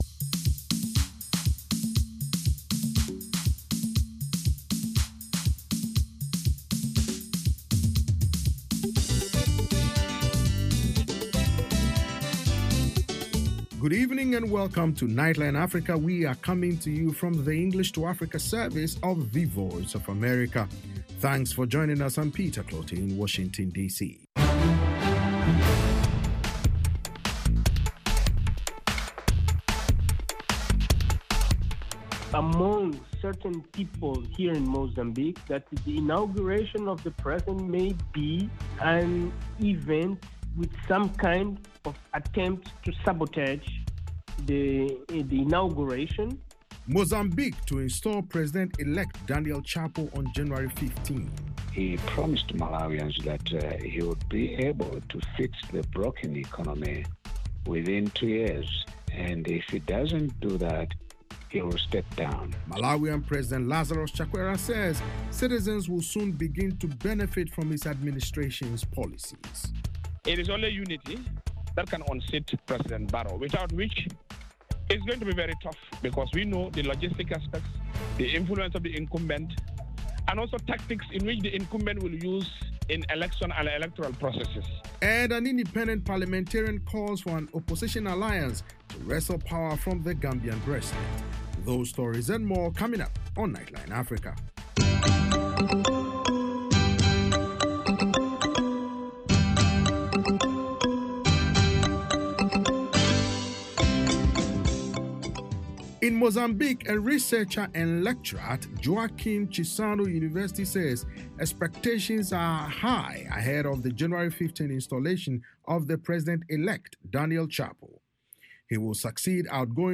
Nightline Africa is a 60-minute news magazine program highlighting the latest issues and developments on the continent. Correspondents from Washington and across Africa offer in-depth interviews, analysis and features on African arts and culture, sports, and music